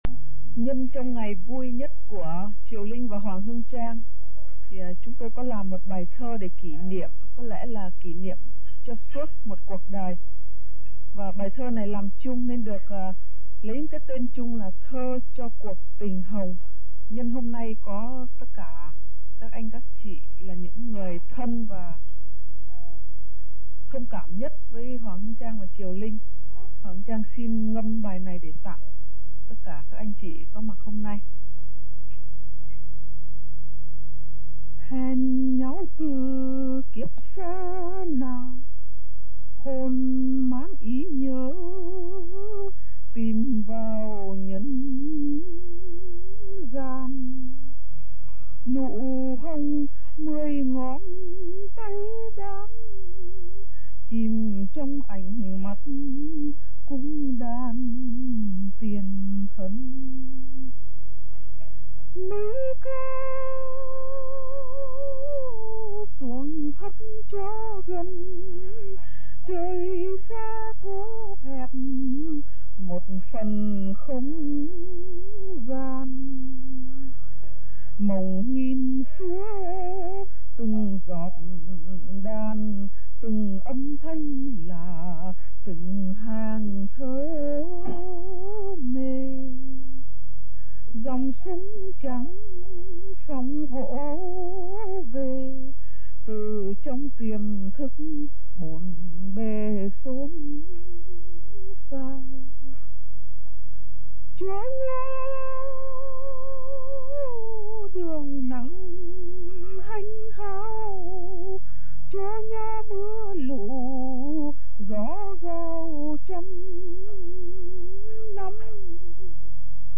Những bài trên đây là "xuất khẩu thành ngâm/ xuất khẩu thành ca" , không có nhạc đệm.